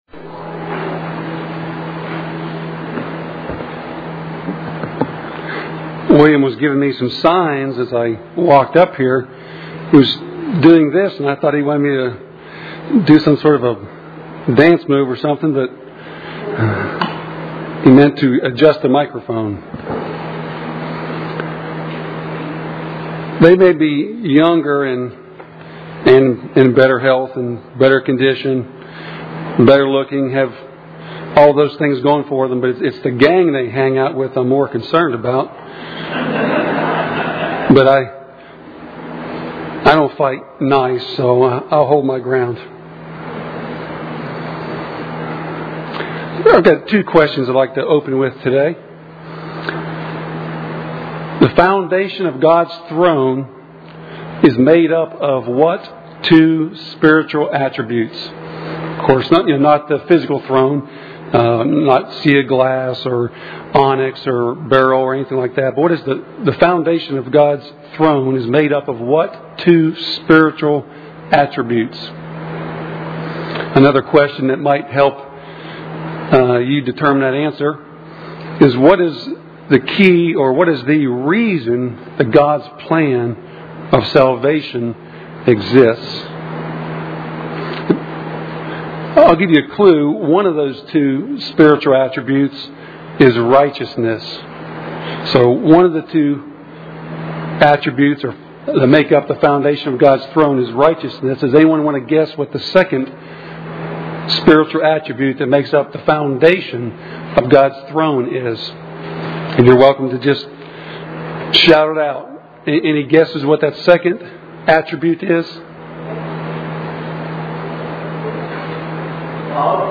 Given in Nashville, TN
UCG Sermon Studying the bible?